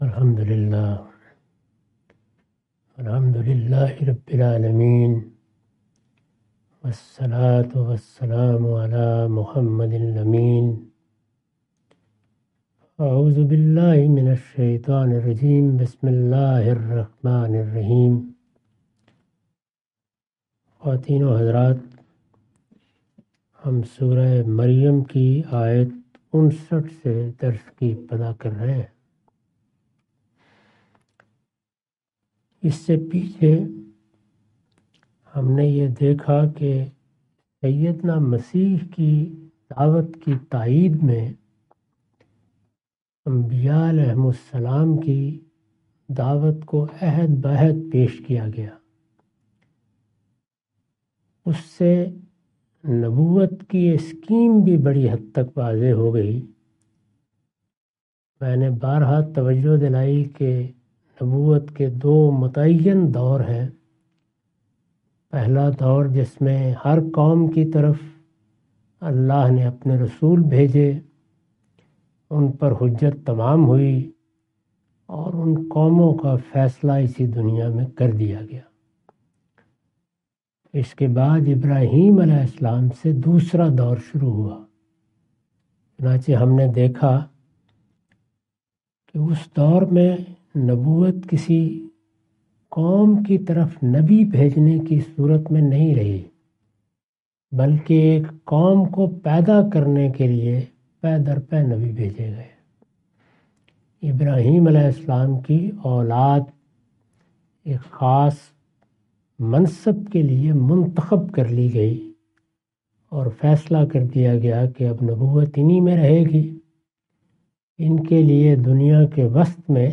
Surah Maryam A lecture of Tafseer-ul-Quran – Al-Bayan by Javed Ahmad Ghamidi. Commentary and explanation of verses 59-61.